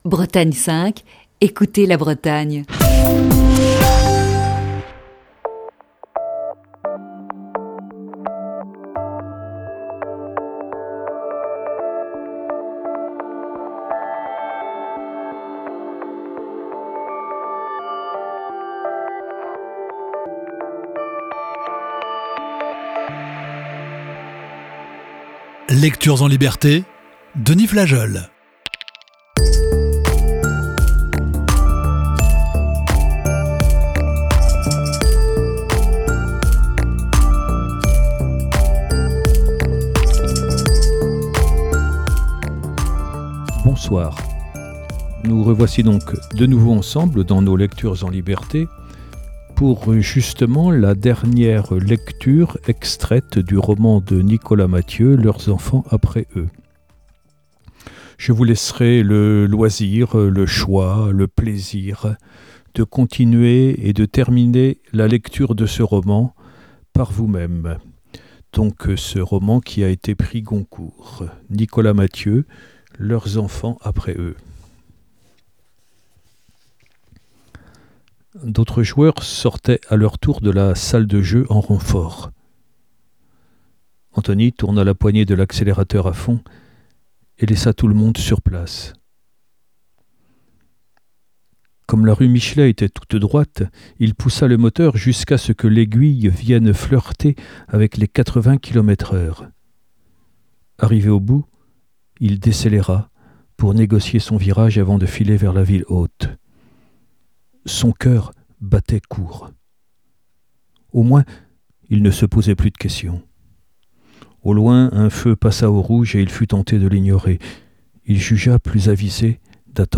Émission du 1er mai 2020.